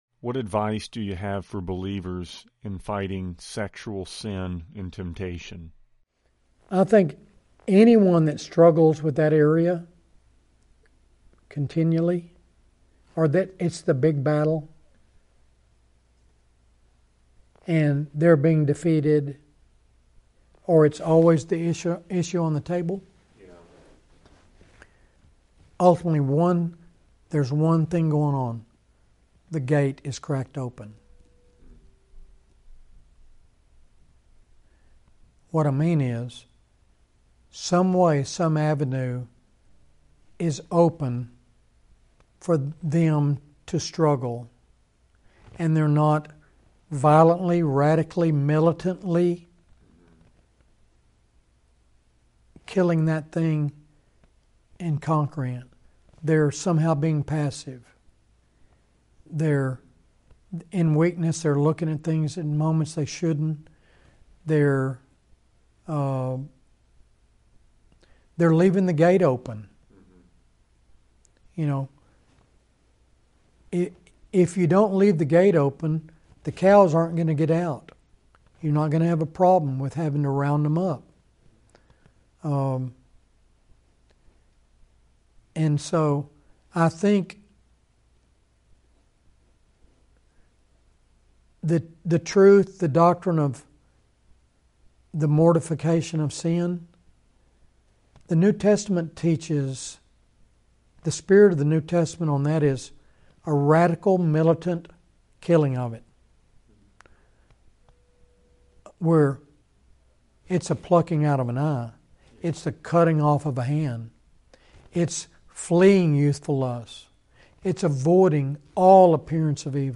Interview | 6:20 | For those who are falling into sexual sin, the root cause is that they've opened a door for it somewhere in their life.